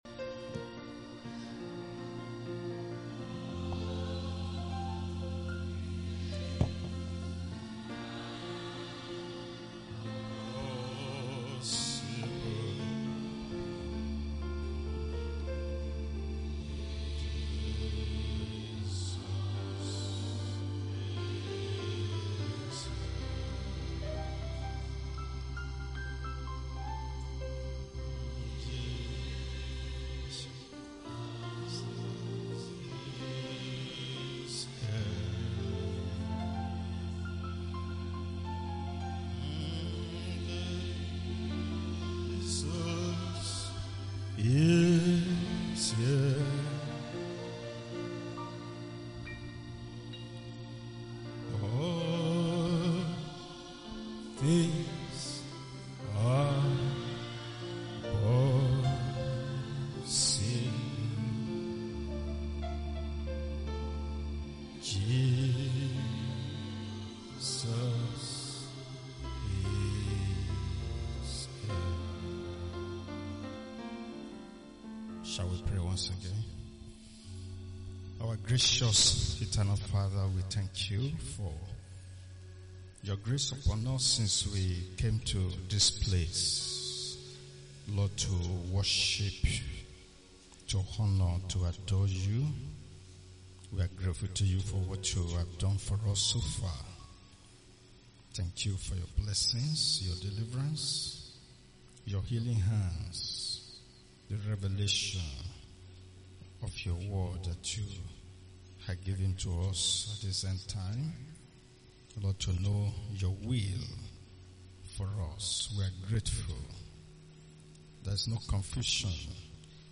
SUNDAY PM SERVICE